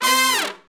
Index of /90_sSampleCDs/Roland L-CD702/VOL-2/BRS_R&R Horns/BRS_R&R Falls